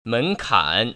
발음 : [ ménkǎn(r) ]